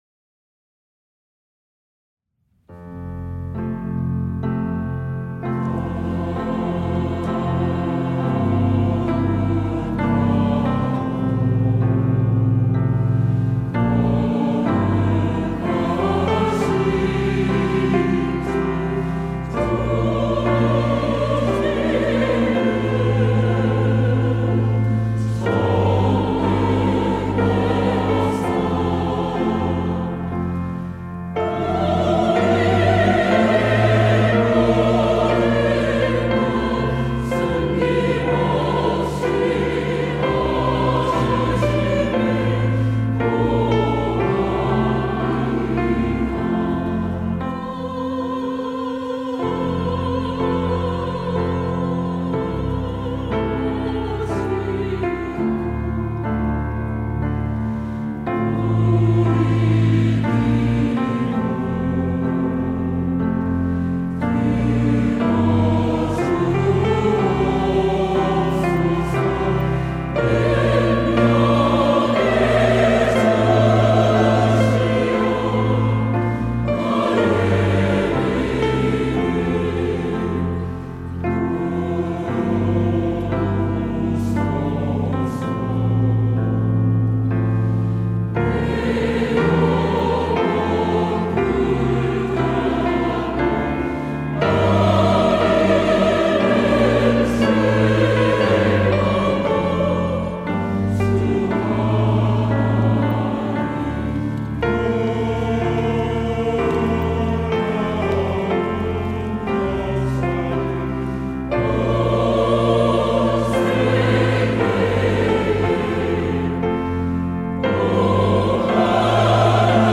시온(주일1부) - 하늘의 아버지
찬양대